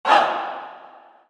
Waka HAH.WAV